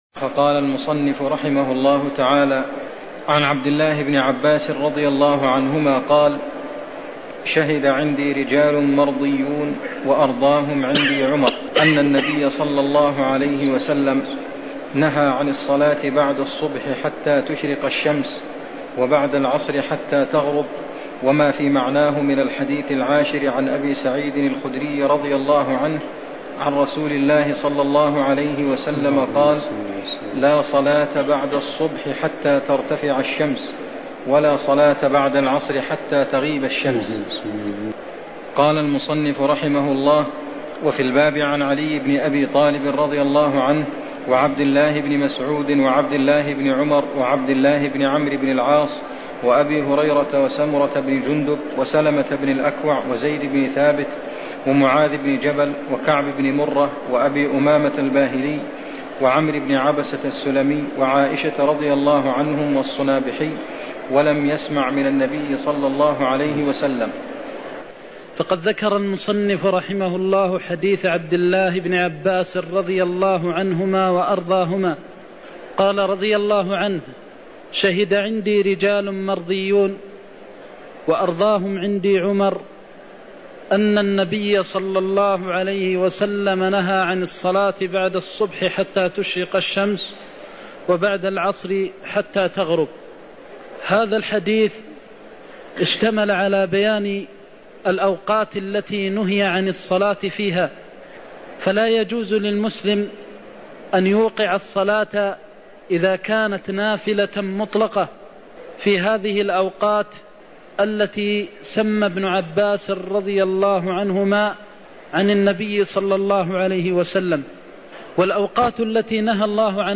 المكان: المسجد النبوي الشيخ: فضيلة الشيخ د. محمد بن محمد المختار فضيلة الشيخ د. محمد بن محمد المختار نهى عن الصلاة بعد الصبح (52)